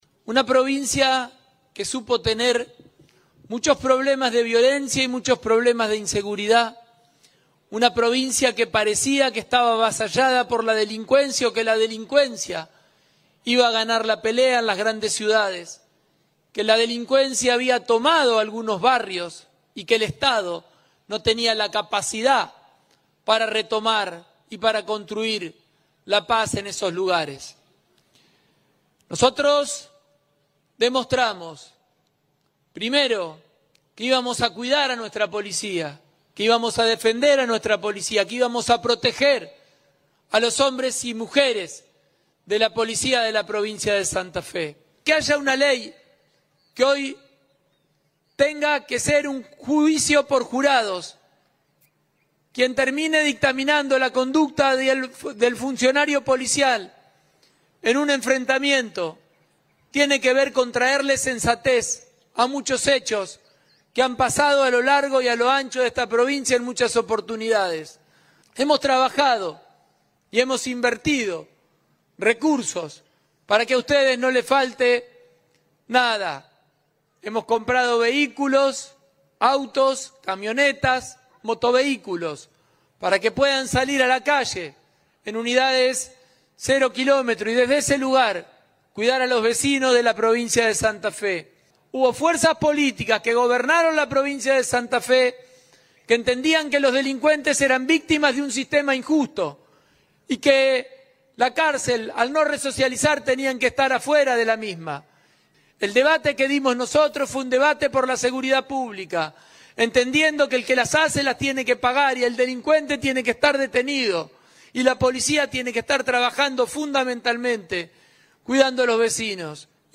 El Parque Federal de la ciudad de Santa Fe fue este domingo escenario del acto por el 161° aniversario de la Policía provincial, encabezado por el gobernador Maximiliano Pullaro, acompañado por la vicegobernadora Gisela Scaglia, el ministro de Justicia y Seguridad Pablo Cococcioni y el jefe de la fuerza, Luis Maldonado.